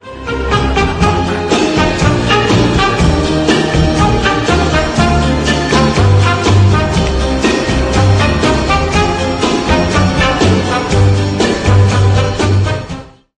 120 - VALSE MUSETTE